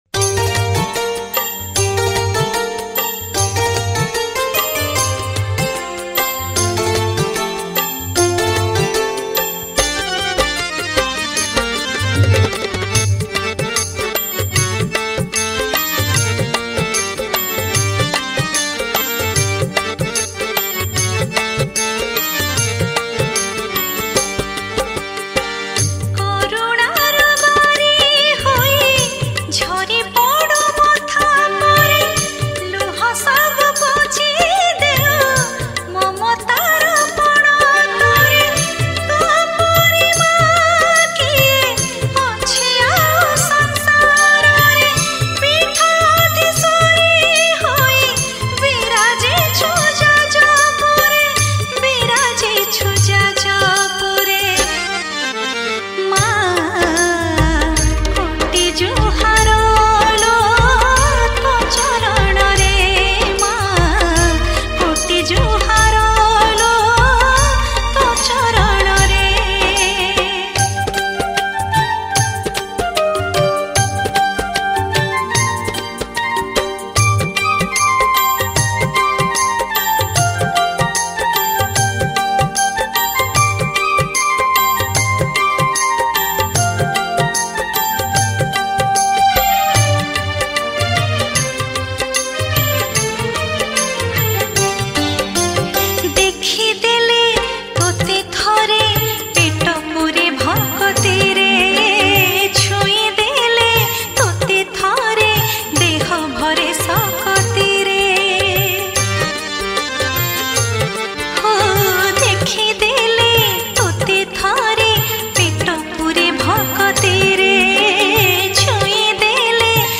New Odia Bhajan Song